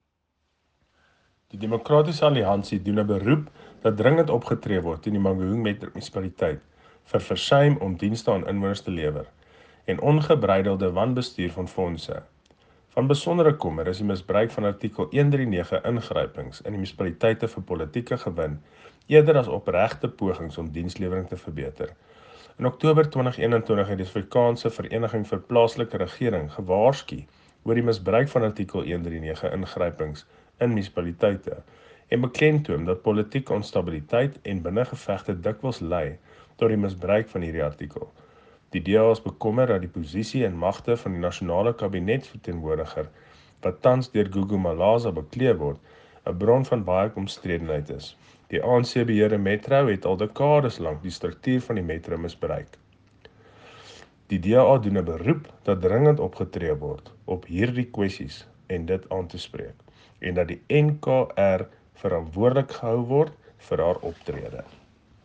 Afrikaans soundbites by Cllr Johan Pretorius and